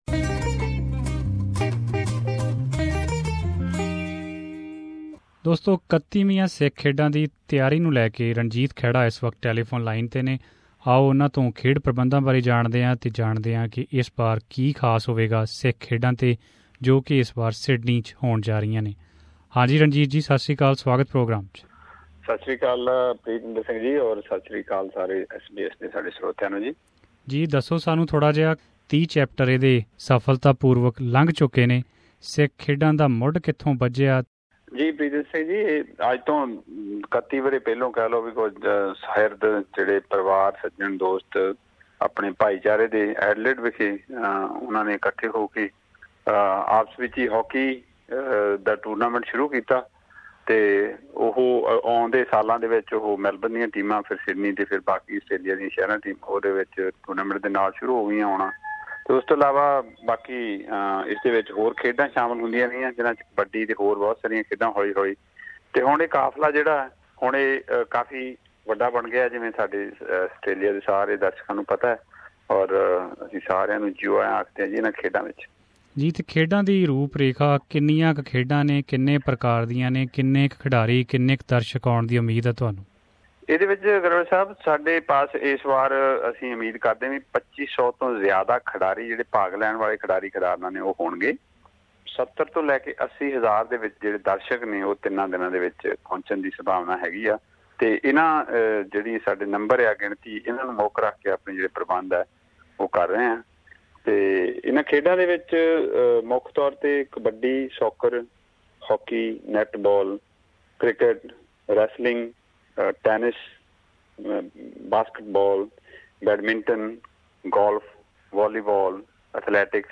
The 31st Australian Sikh Games will be held at Sydney over the Easter weekend from 30th March to 1st April 2018. The games organising committee has invited everyone for this three-day spectacle of sport and culture, which is to be enjoyed by young and old alike. Here we've an interview